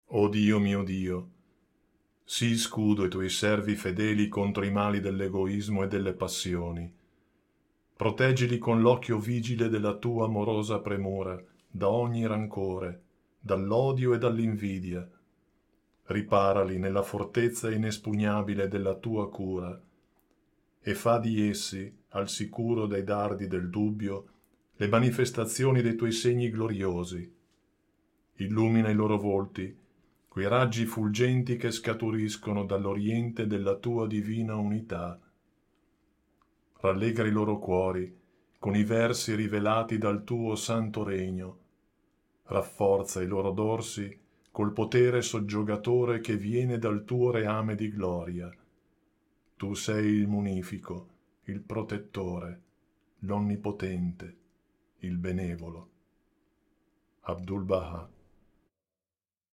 Audiolibri Bahá'í Gratis